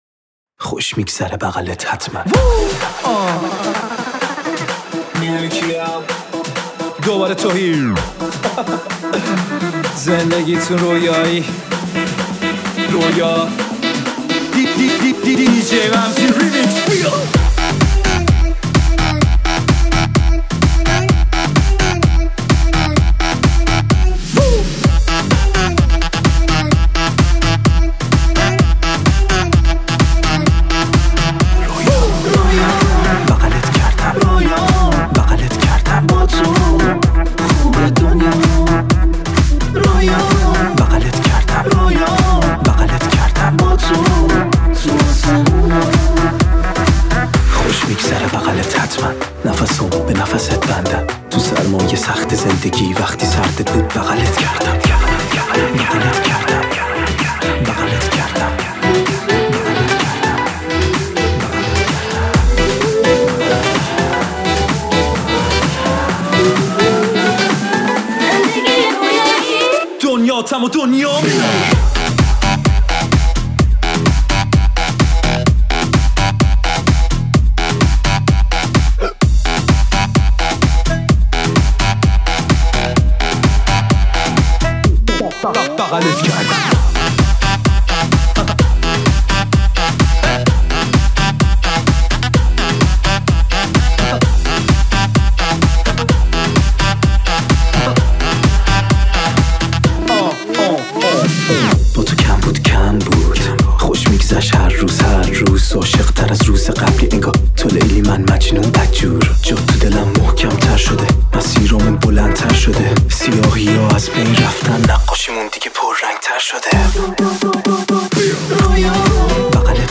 آهنگ شاد عروسی
آهنگ شاد مجلسی